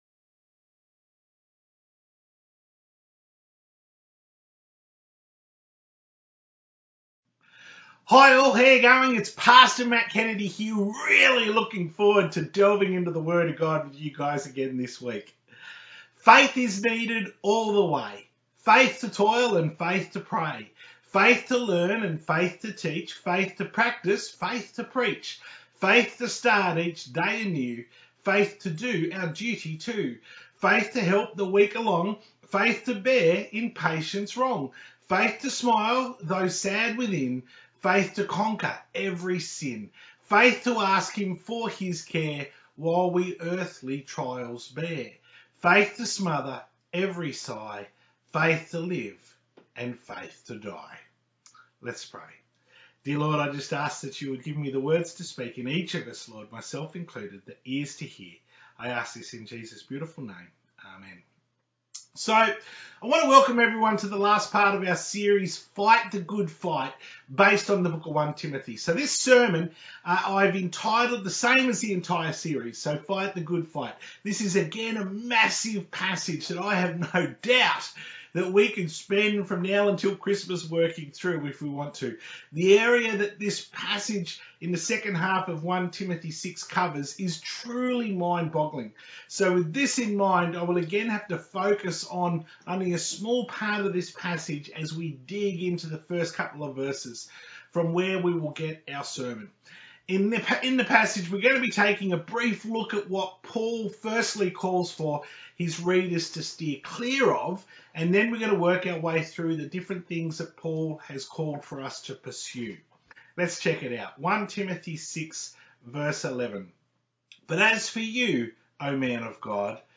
To view the Full Service from 18th October 2020 on YouTube, click here.